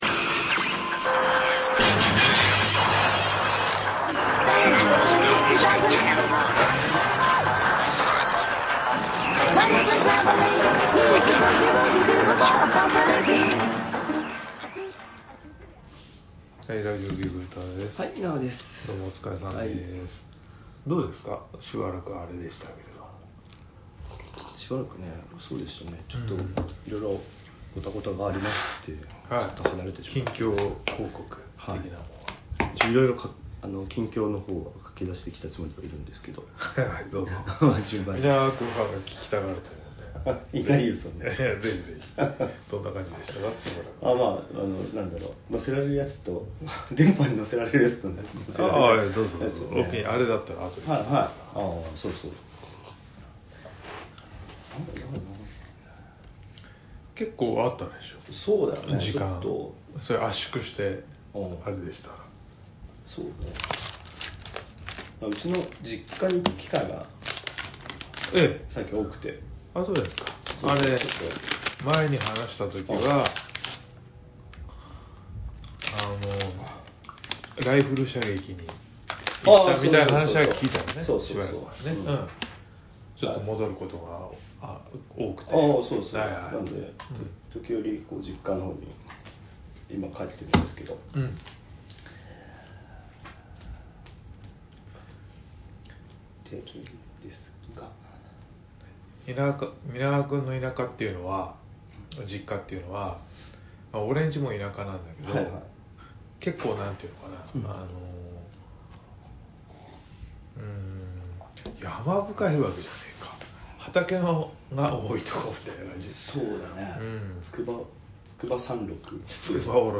アメコミやバンドデシネがちょっと好きで、ちょっとアレな二人の男子が、至高の女子会を目指すエンタテインメントネットラジオです。